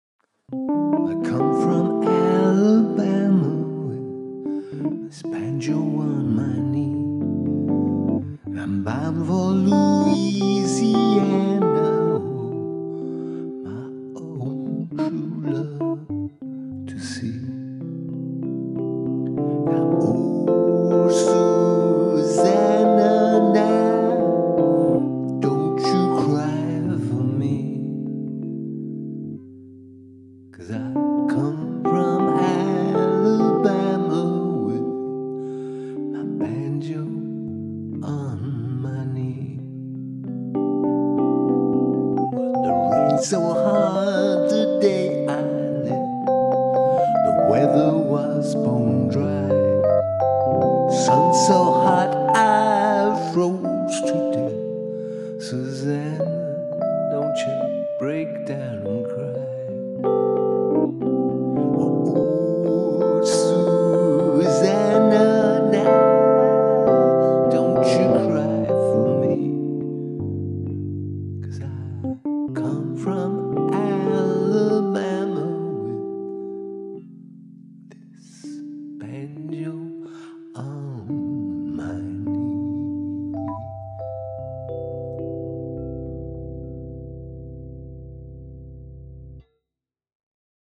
smooth non rhythmic crooning approach
I love the Rhodes synth setting - and beautiful playing! Lovely vocals - so emotive - and I love how both the vocals and keys play with time a bit.
The electric piano sound is such divergence from the normal instrument choice for this song…well done!